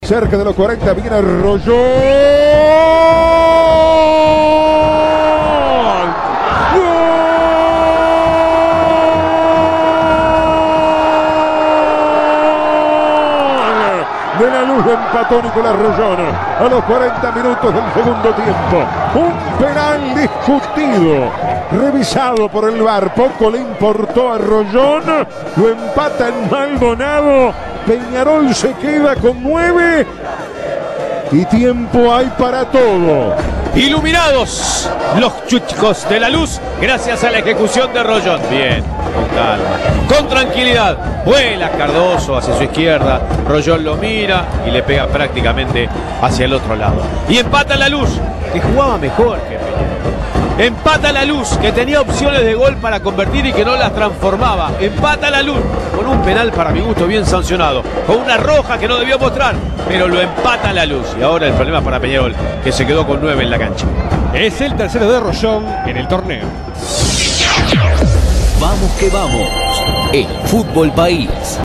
El partido de locos entre merengues y carboneros en ma voz del equipo de VQV